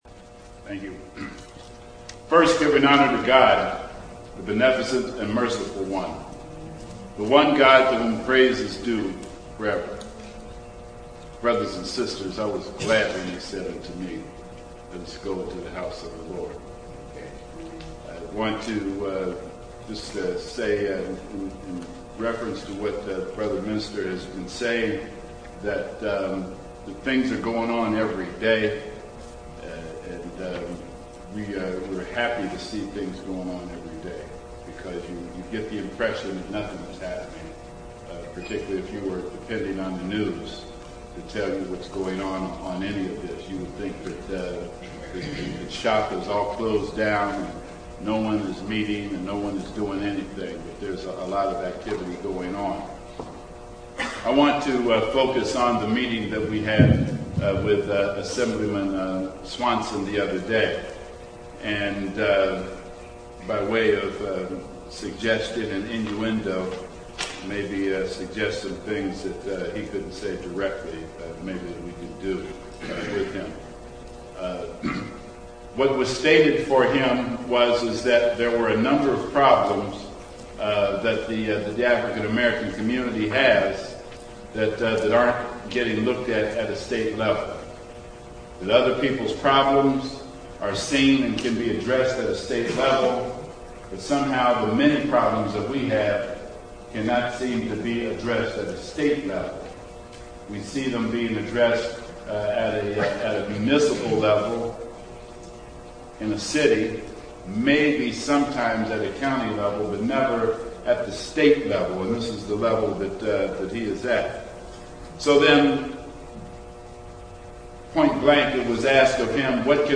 Town Hall for Justice on AB312, BART, Orloff Recall, and Caravan for Justice III, Oakland, 4/18/09: audio